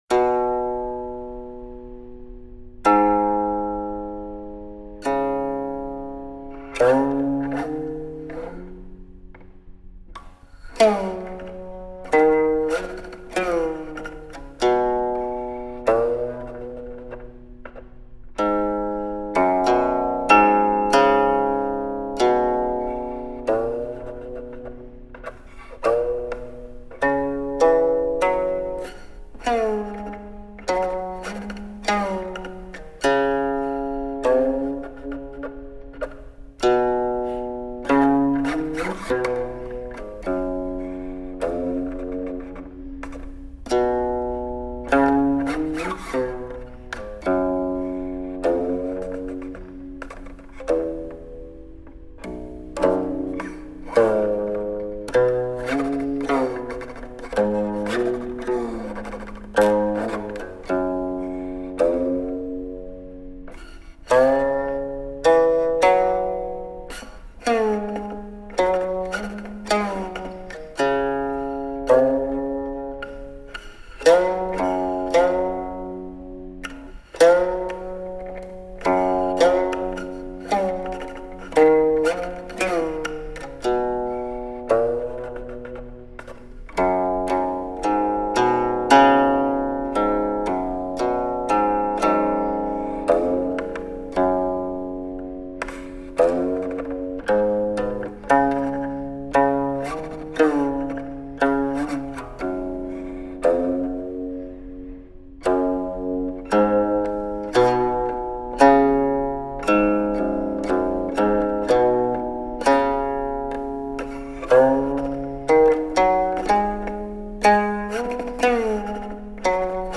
II.  Qin on every track, multiple players on the CD
All silk string qin; * = recorded before 1965